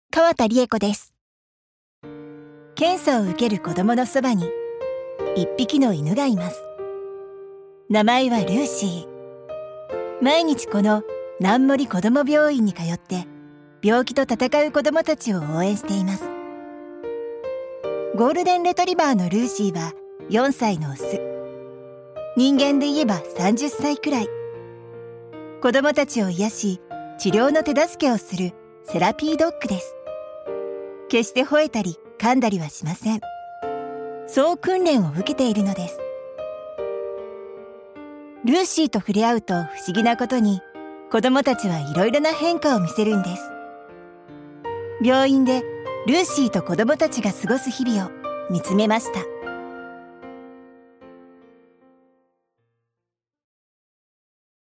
ボイスサンプル
• クールトーンボイス
• 音域：高～中音
• 声の特徴：クール、ナチュラル、さわやか
味わいのある低めの声は、サラリとしたクールさの中にあたたかさを感じさせます。